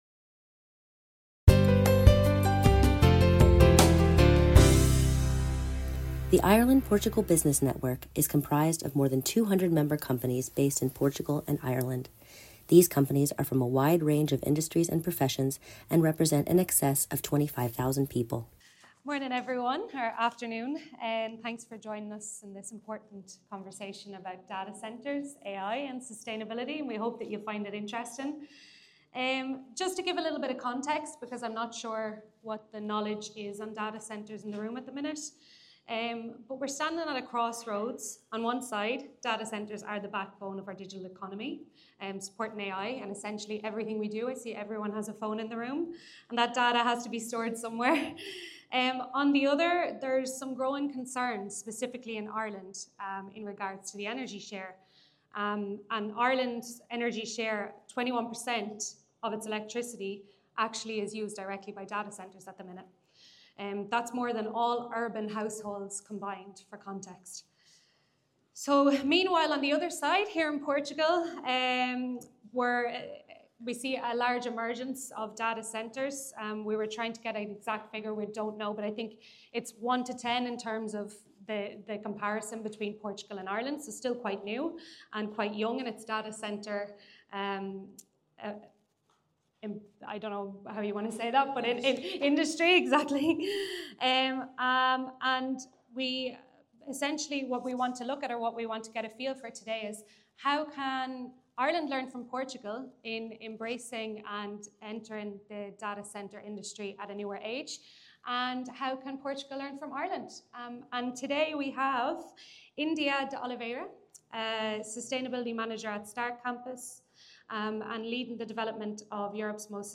Ireland Portugal Business Network Podcast series - IPBN Podcast Series S02 E07: Panel 3 Discussion on Sustainable AI-Scale Data Centres with Innovative Cooling and 100% Renewable Energy